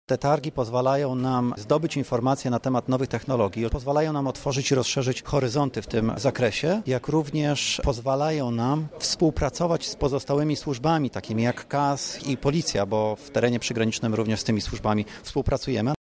Targi „Granice”
• dodaje Praga.